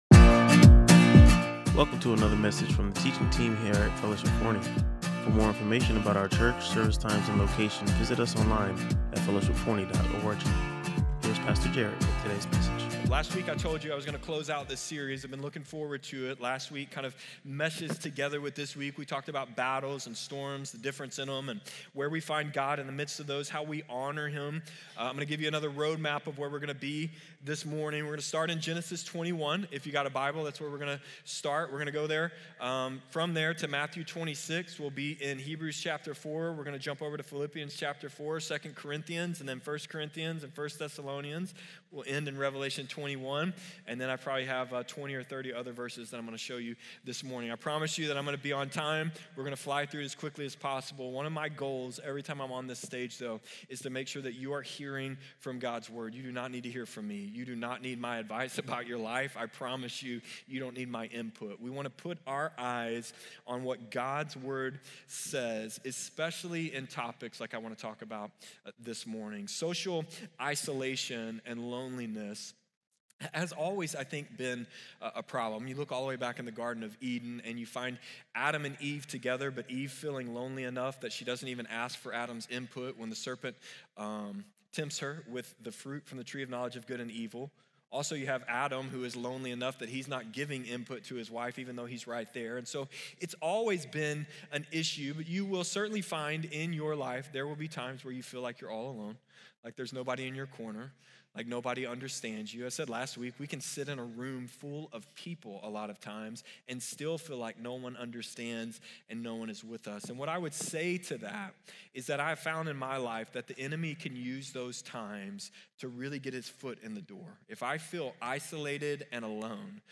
Listen to or watch the complete sermon and discover how God’s grace sustains us through seasons of loneliness.